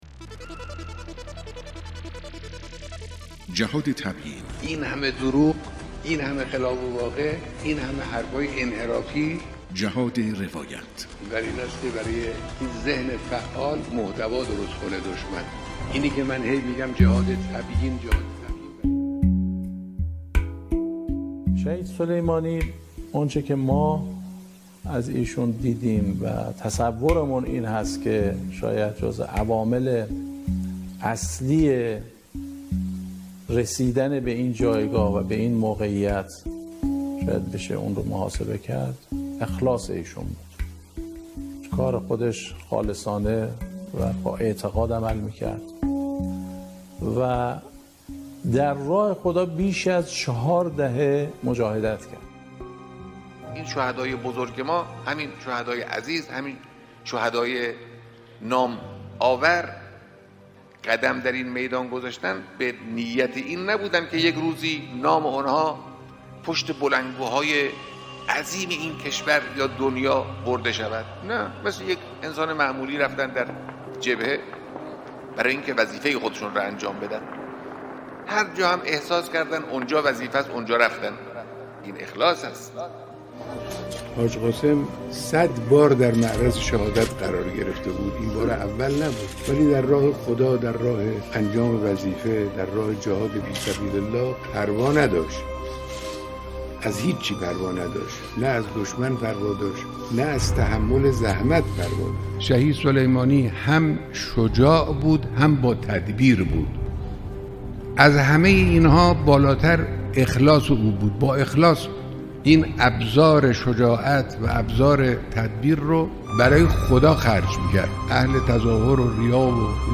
بیانات مقام معظم رهبری